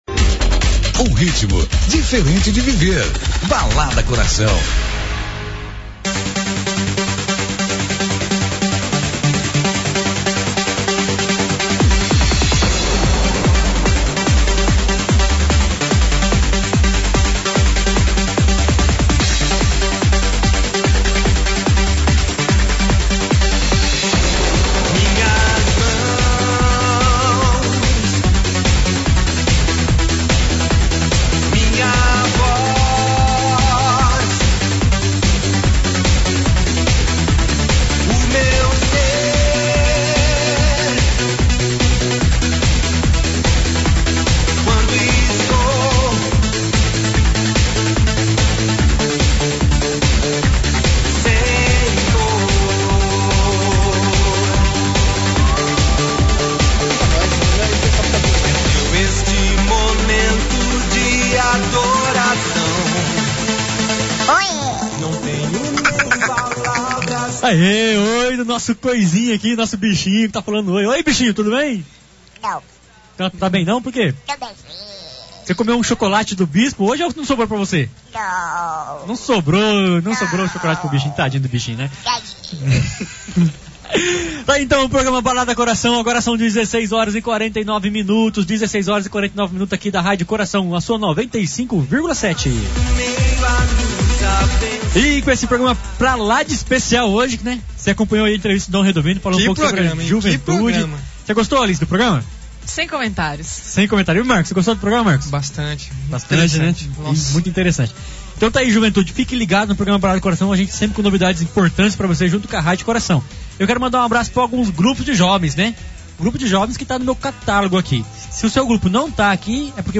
Entrevista dia 14 de Agosto